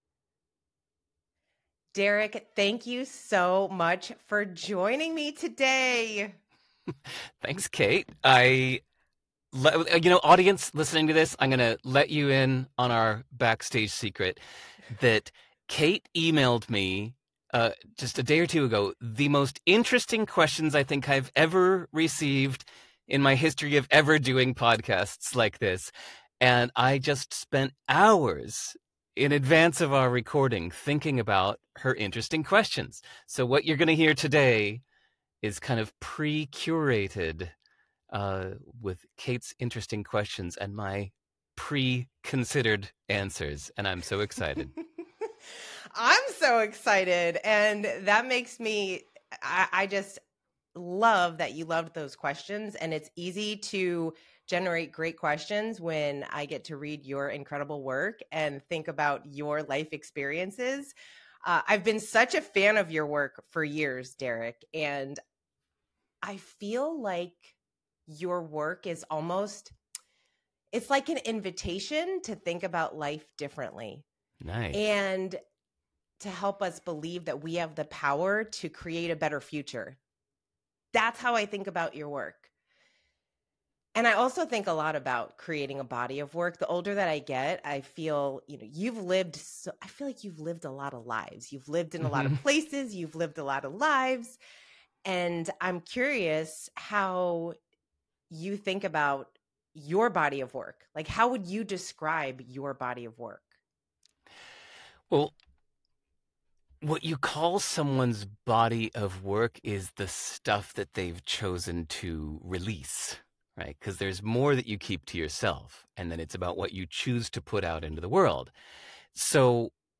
Interviews: